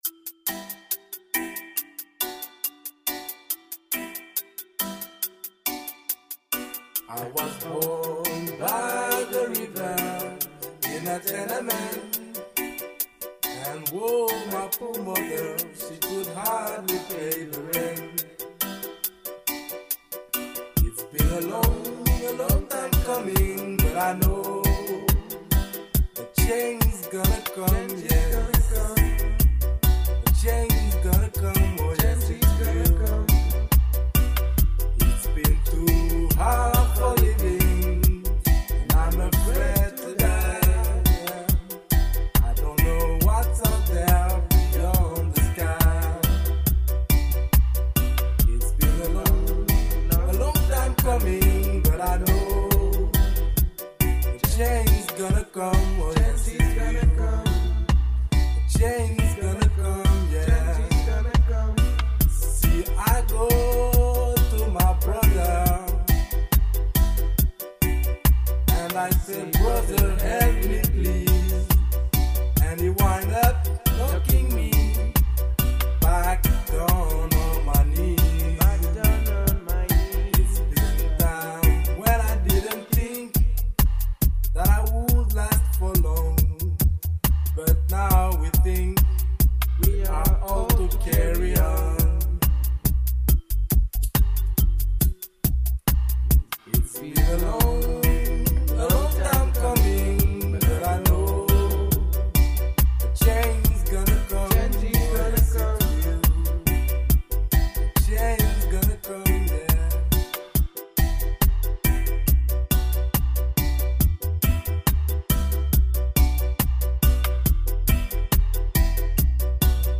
Riddim
Vocals